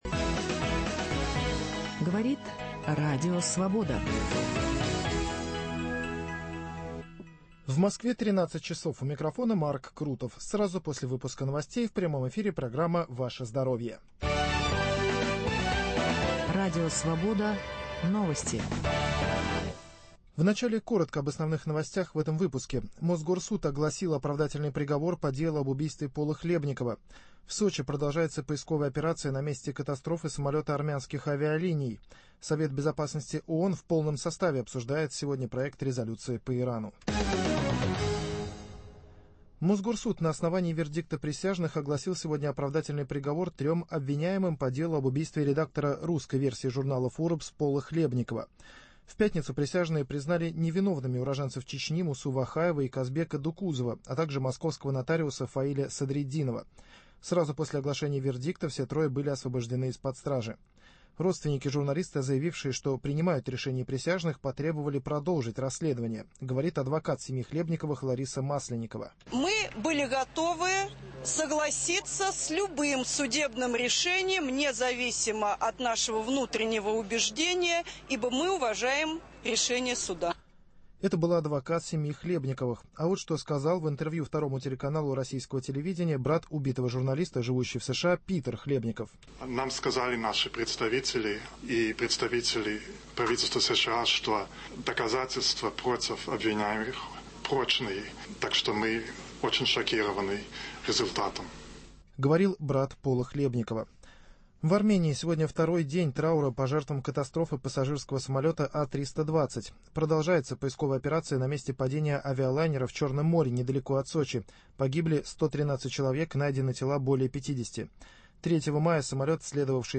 Мы поговорим о проблемах челюстно-лицевой хирургии – о судьбе наших протезов, имплантов и других видов стоматологической помощи при тех осложнениях, которые возникают неизбежно в результате остеопороза, пародонтита, различных травм, различных заболеваний, таких как онкология на челюсти, на лице. Гости студии